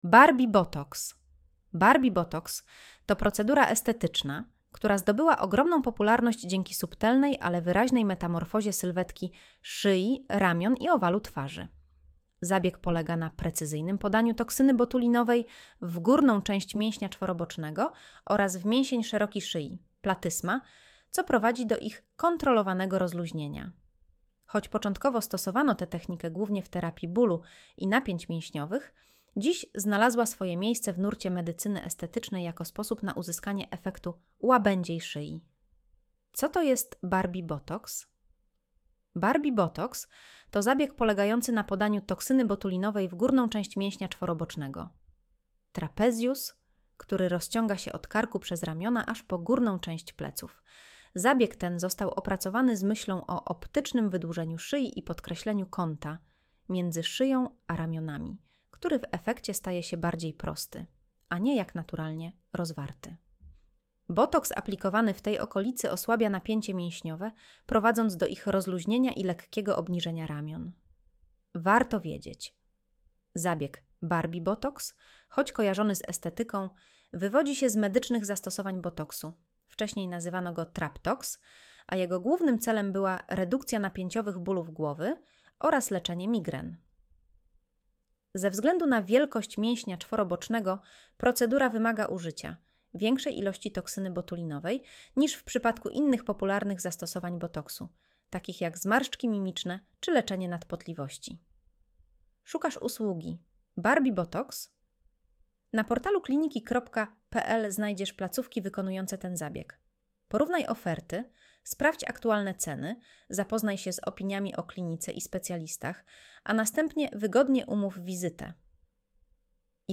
Posłuchaj (08:01 min) Streść artykuł Słuchaj artykułu Audio wygenerowane przez AI, może zawierać błędy 00:00 / 0:00 Streszczenie artykułu (AI): Streszczenie wygenerowane przez AI, może zawierać błędy Spis treści Co to jest Barbie botox?